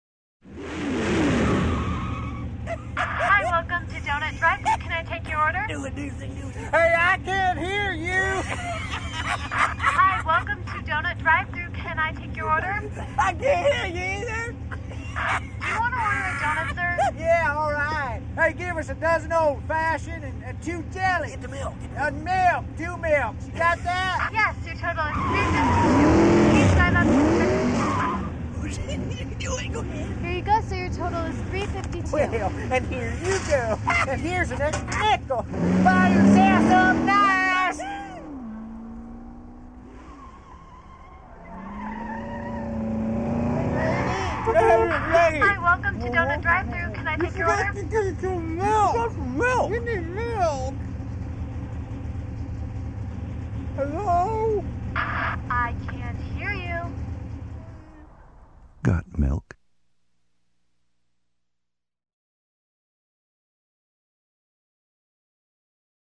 GS&P turned to radio for its unique ability to create great theater-of-mind (and thirst-for-milk), as here:
drivethru_cafluidmilk.mp3